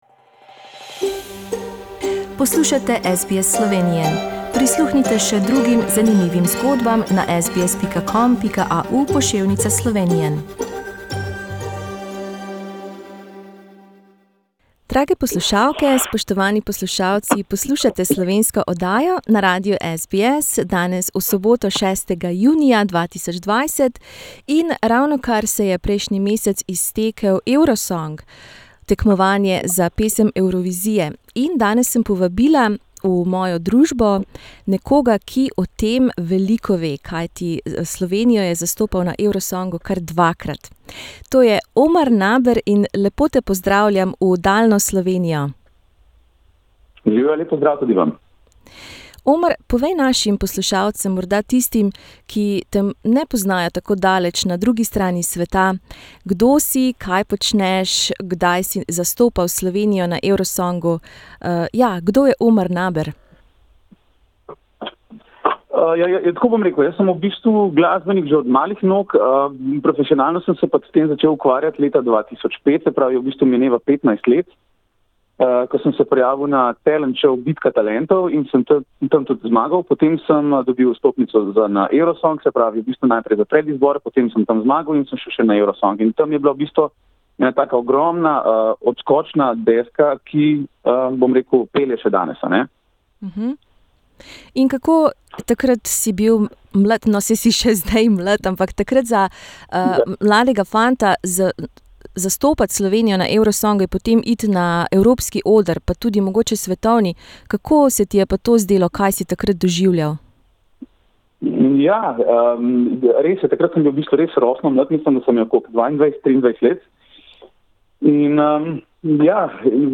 Slovenski pevec, kitarist in avtor skladb Omar Naber je Slovenijo zastopal na Evrosongu kar dvakrat, leta 2005 in 2017. V pogovoru za našo oddajo je povedal, da se vrača h koreninam.